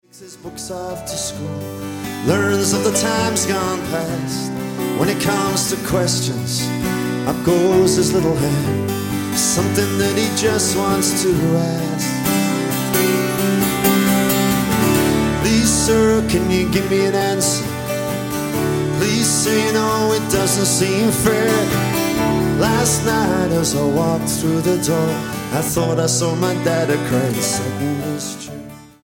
STYLE: Roots/Acoustic
keyboards